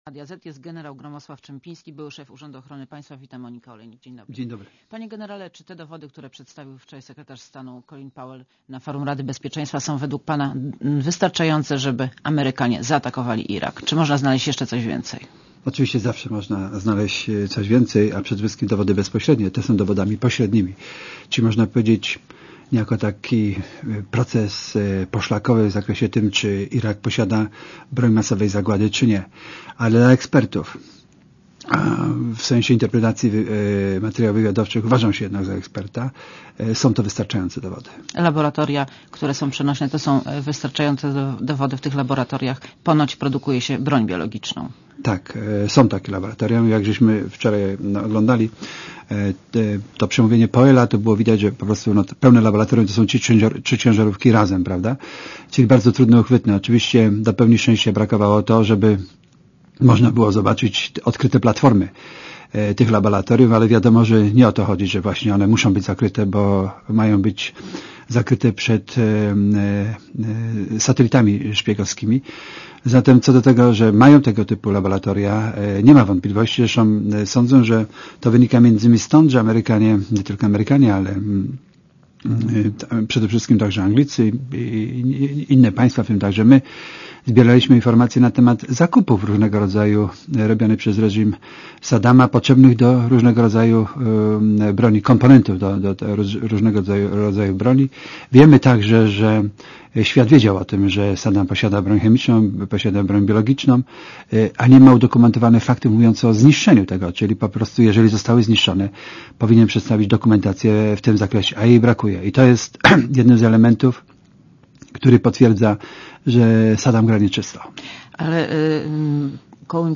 Monika Olejnik rozmawia z Gromosławem Czempińskim - byłym szefem Urzędu Ochrony Państwa